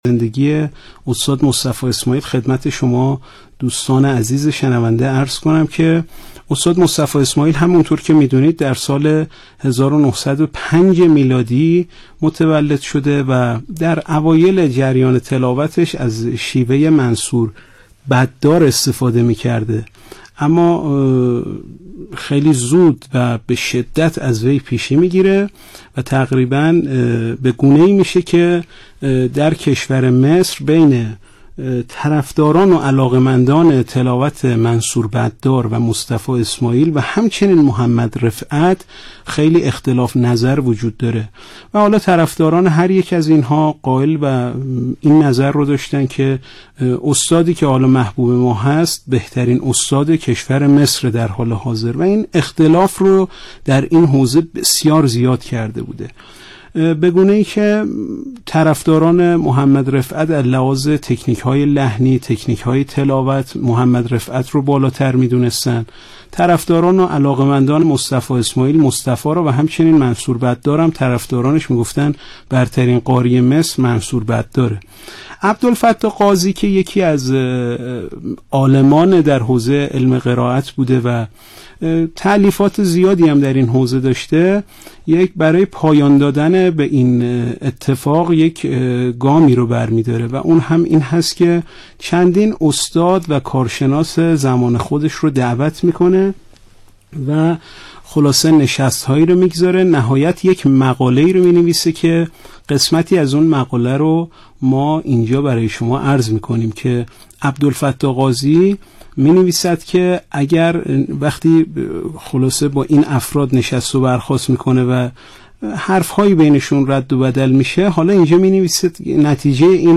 تلاوت استاد «مصطفی اسماعیل» سوره‌های ق، ذاریات 22 تا آخر، طارق و اخلاص
ورود بسیار موزون و دل‌انگیز، کم کم به مقام حجاز و در اوج جواب جواب‌هایی که در این قطعه می‌شنویم، بسیار گوش‌نواز و آموزشی است.